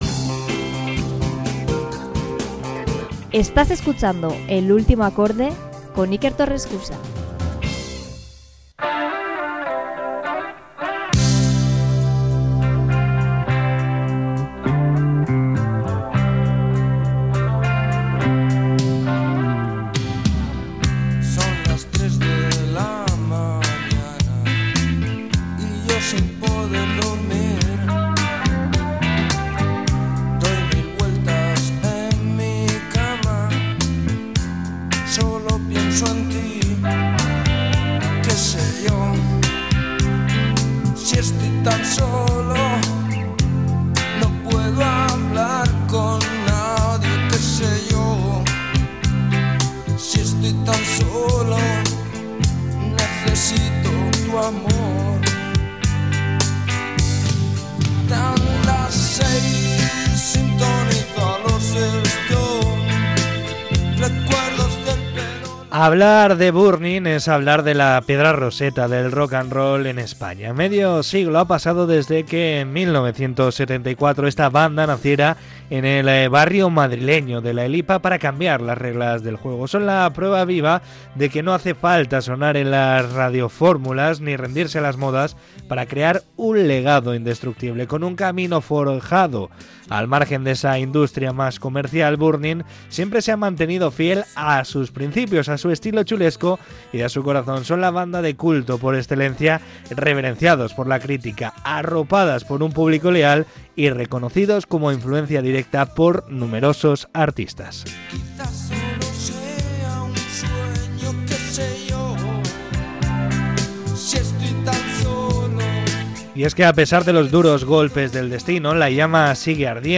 Entrevista con el líder de Burning, Johnny Cifuentes